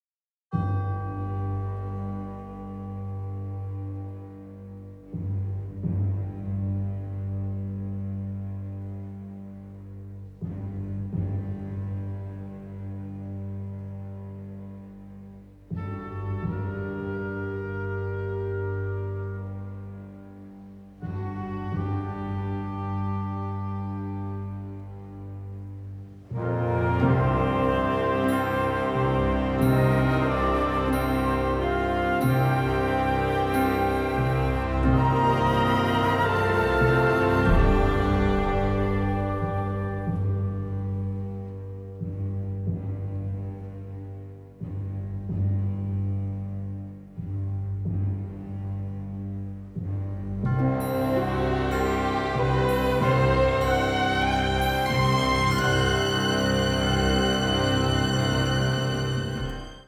mirroring reality with melancholic orchestration.